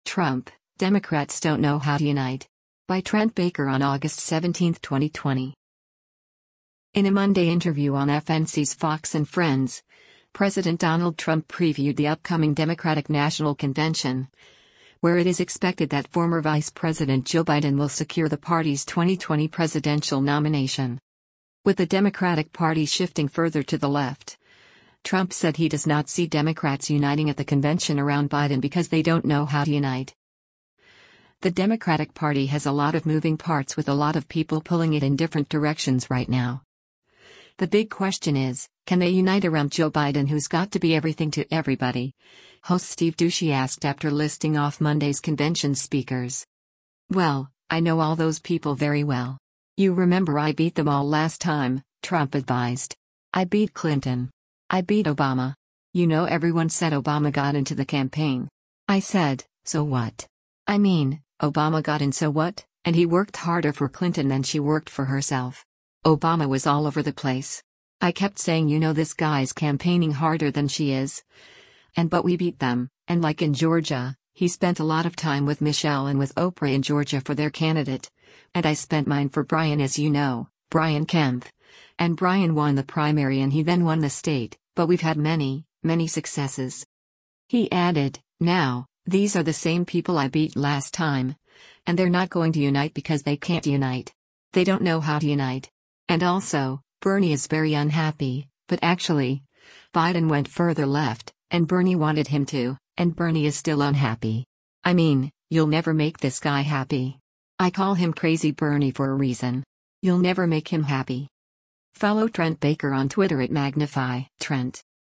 In a Monday interview on FNC’s “Fox & Friends,” President Donald Trump previewed the upcoming Democratic National Convention, where it is expected that former Vice President Joe Biden will secure the party’s 2020 presidential nomination.